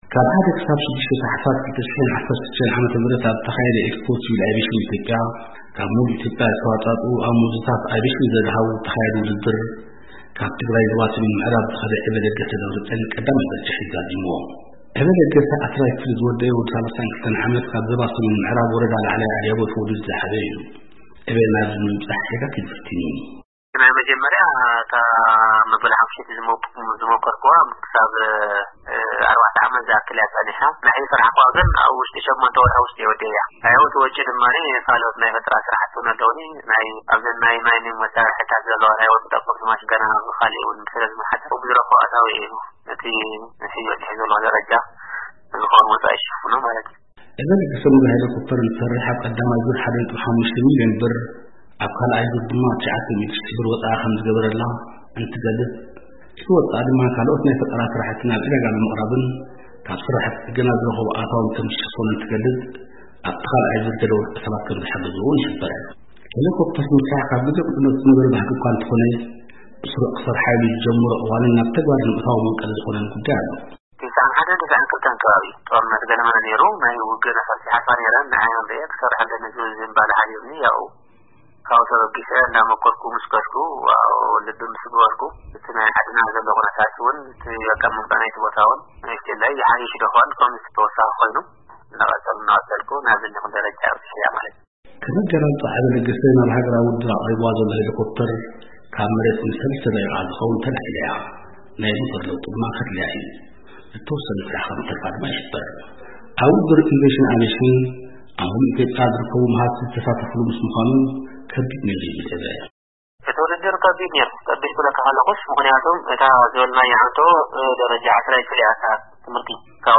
ቃለ መሕትት ምስ ዕዉት ኤክስፖ ኣቭየሽን ኢትዮጵያ